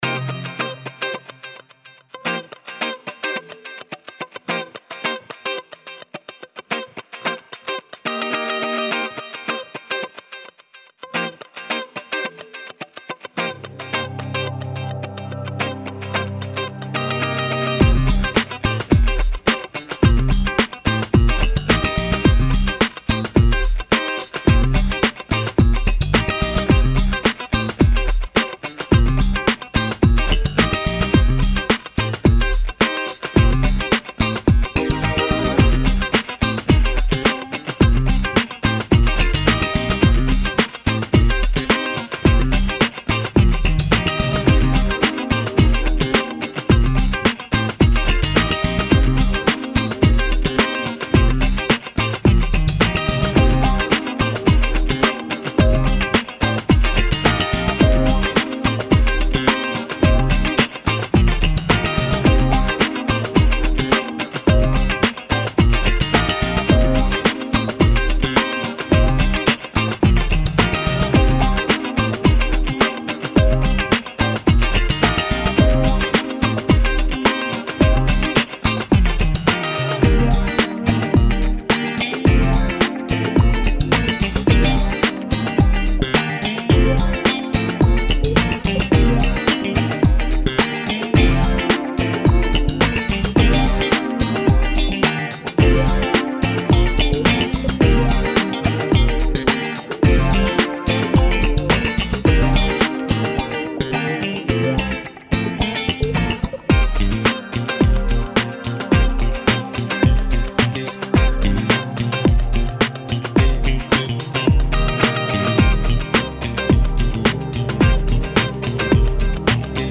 funk - emploi - ordinateur